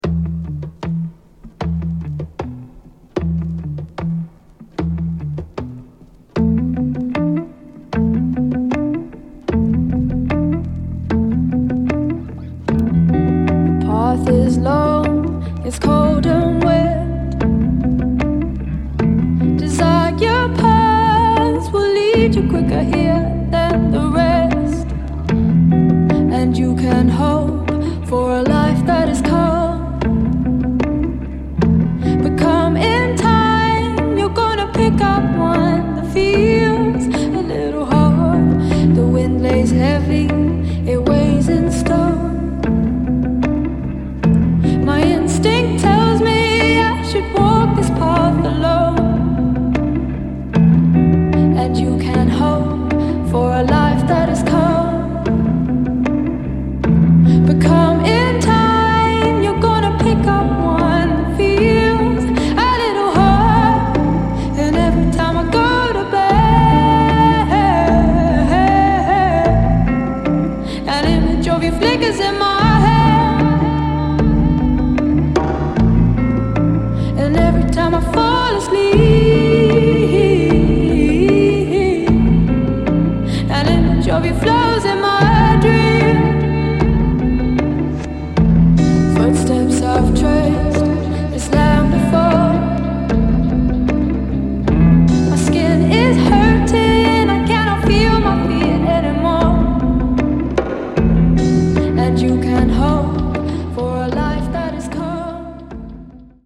Pop, Rock, Psyche, Folk